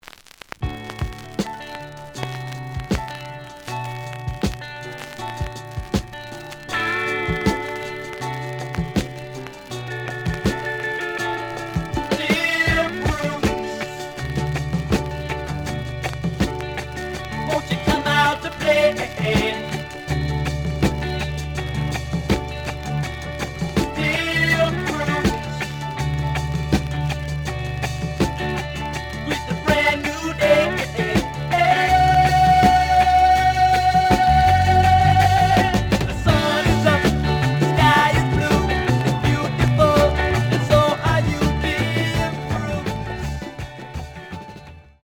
The audio sample is recorded from the actual item.
●Genre: Soul, 70's Soul
Some noise on B side.)